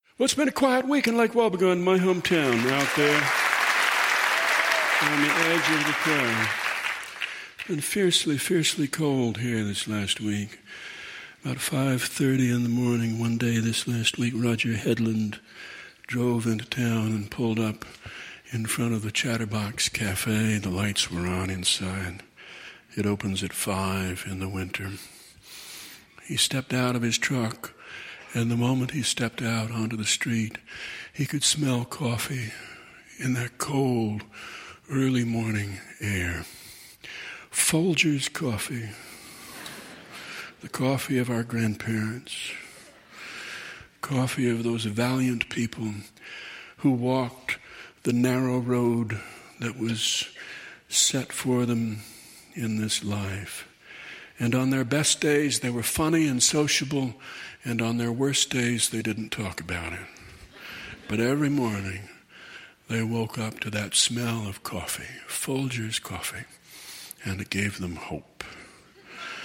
PS Loved the Garrison Keillor voice.